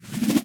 Minecraft Version Minecraft Version latest Latest Release | Latest Snapshot latest / assets / minecraft / sounds / mob / breeze / charge3.ogg Compare With Compare With Latest Release | Latest Snapshot
charge3.ogg